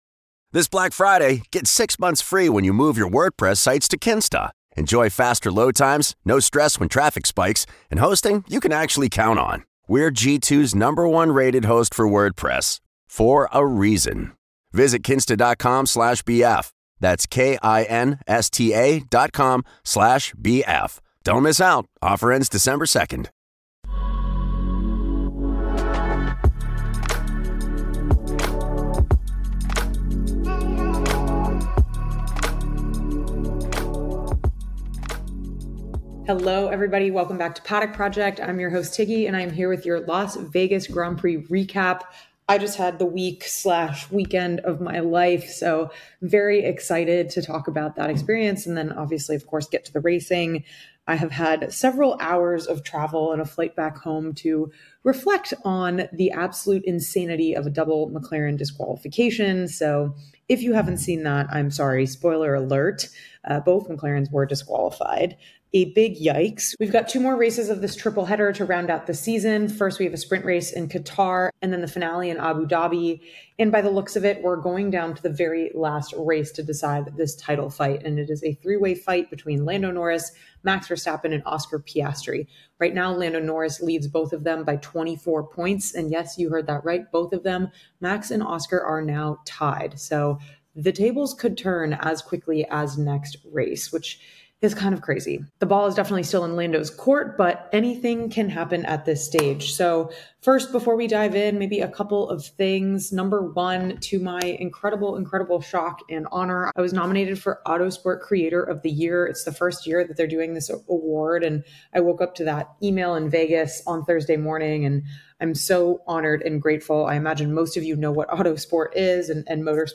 Through interviews with drivers, team members, and experts